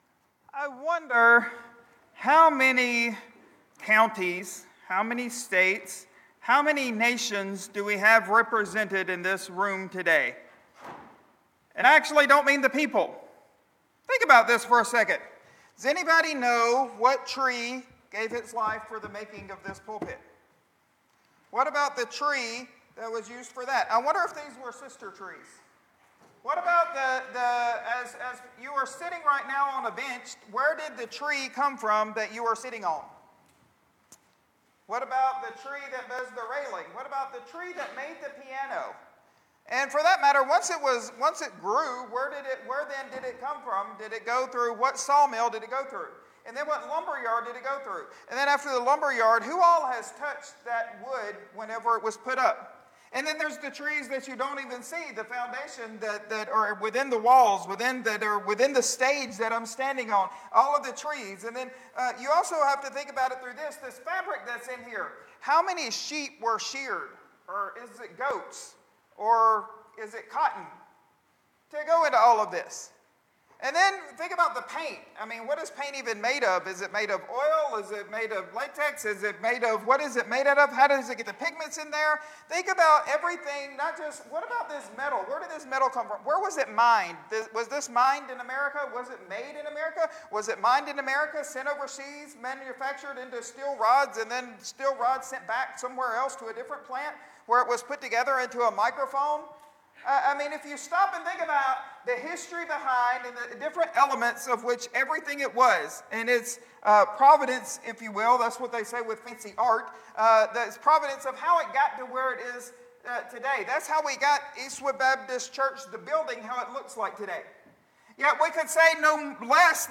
Sermons | Eastwood Baptist Church